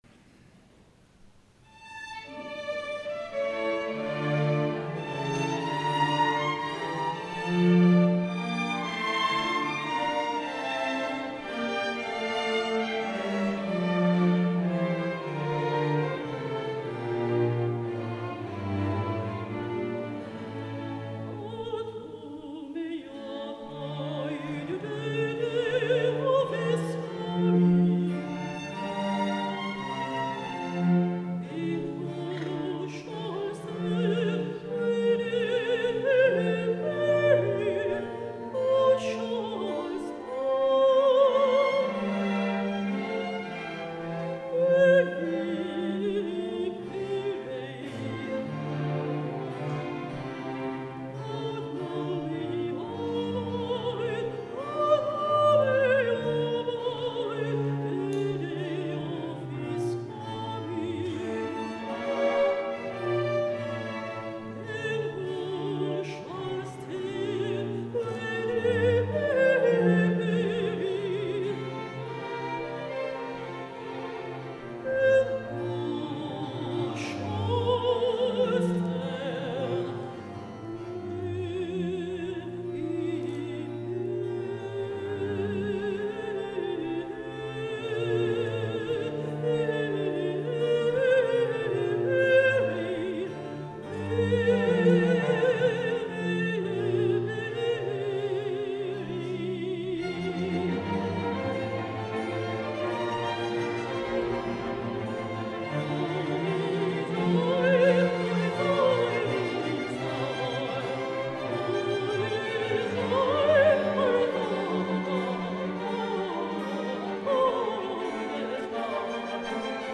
S. Gaudenzio church choir Gambolo' (PV) Italy
Chiesa Parrocchiale - Gambolò
Concerto di Natale